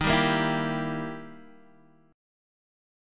Gm6/Eb chord